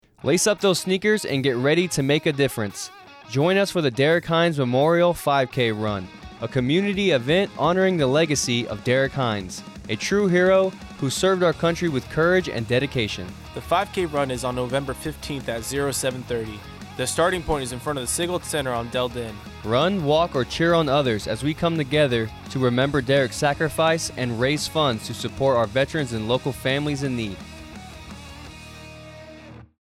AFN Radio Spot